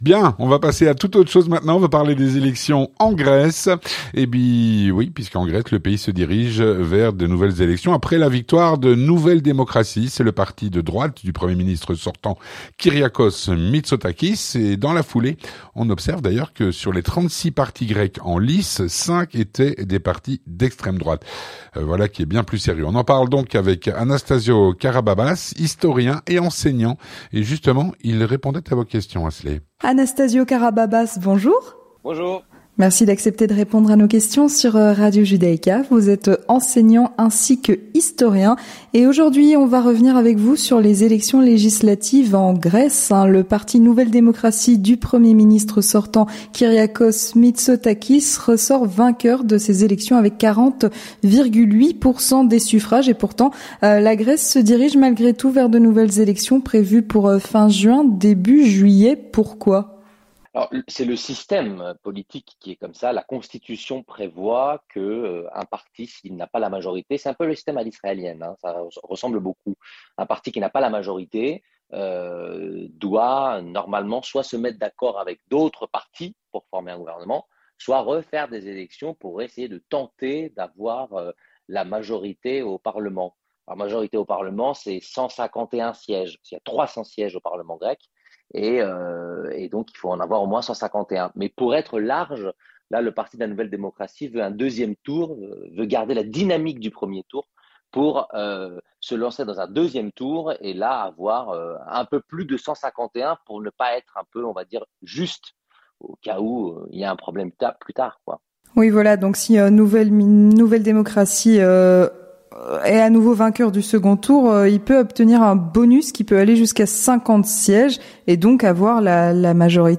Entretien du 18h - Les élections législatives en Grèce